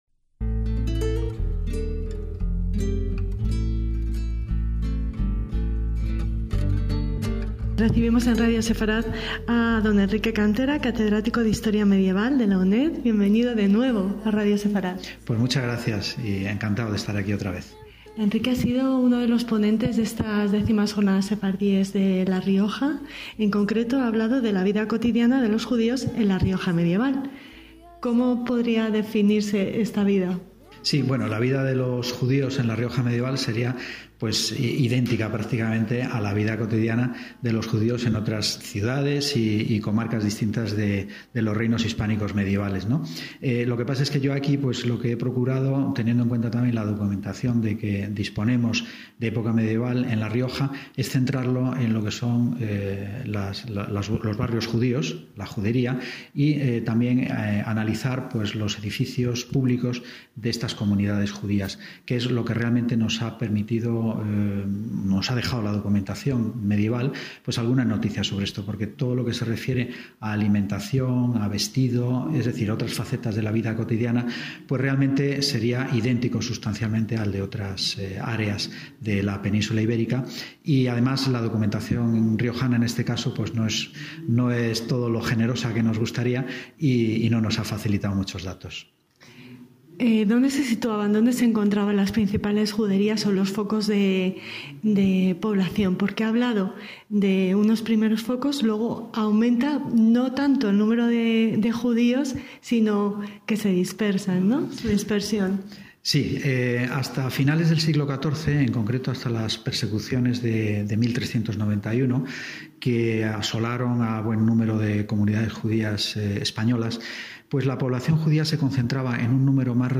DESDE LAS X JORNADAS SEFARDÍES EN LA RIOJA - ¿Quiénes eran, cuántos, dónde se establecieron y cómo vivían los judíos de la Rioja medieval? ¿Cuáles eran sus ritos, sus costumbres, cómo eran sus sinagogas y escuelas, a qué oficios se dedicaban?